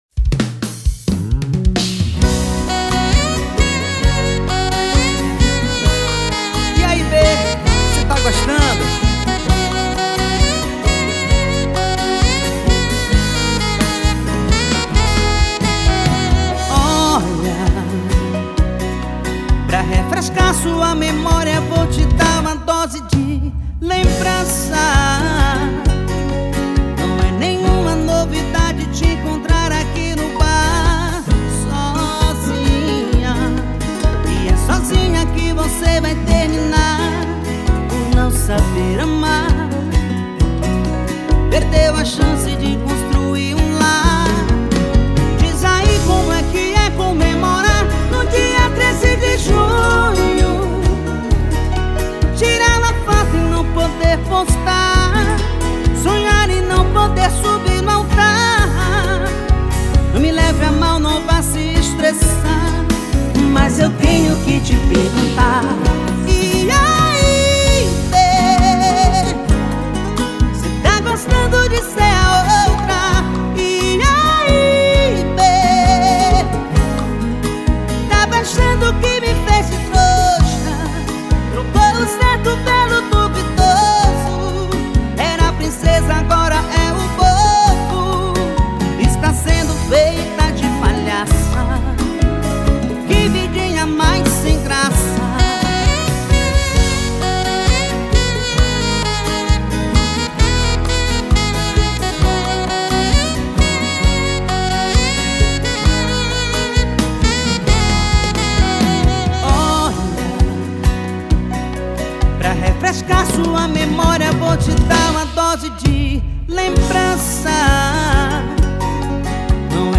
Sertanejo Views